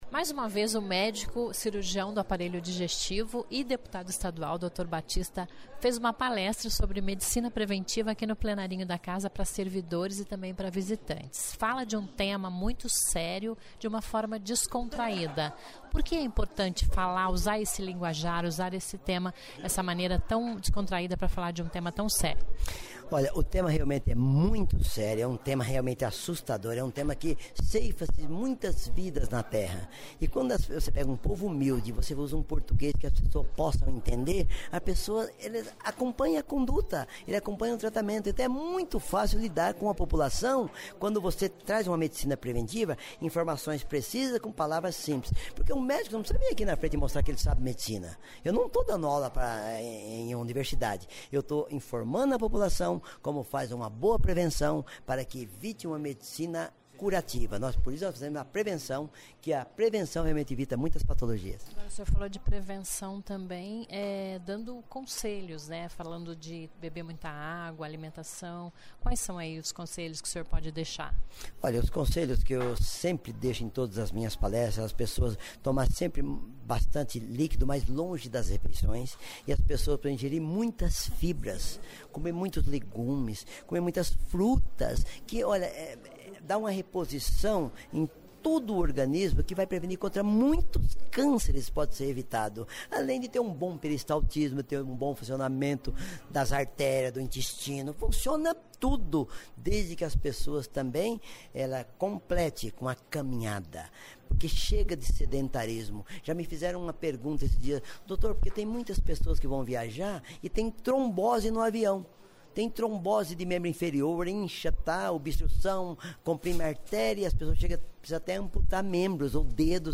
Ouça a entrevista com o deputado Dr. Batista (PPMN), onde ele dá dicas de vida saudável  e fala da importância de não se automedicar.  Ele fez palestra para servidores e visiatntes da Alep na manhã desta segunda-eira (26), no Plenarinho da Casa.